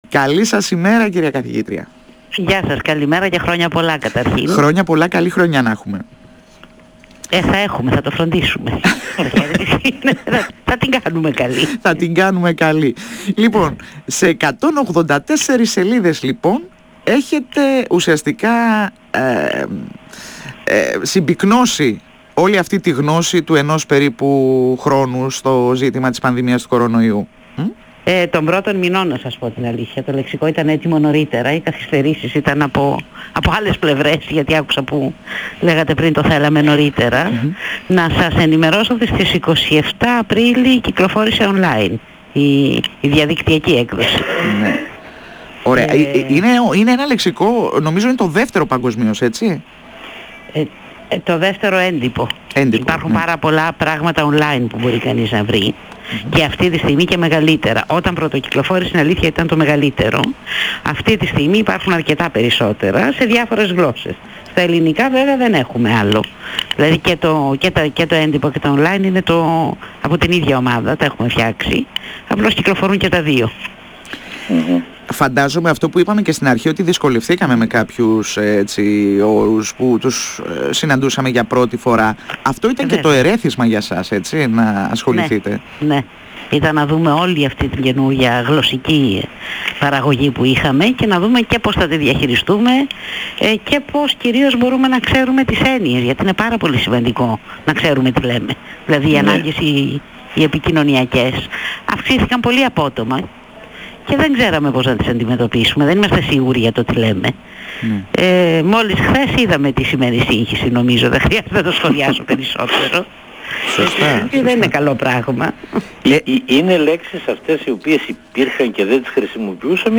102FM Συνεντεύξεις